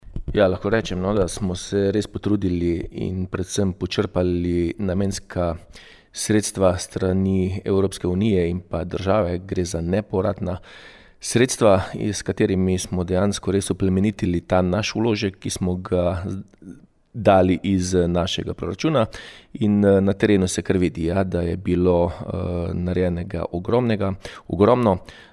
Občinski praznik je priložnost, da župan lahko še glasneje spregovori o uspehih, zaključenih naložbah in viziji Slovenj Gradca:
IZJAVA KLUGLER 2.mp3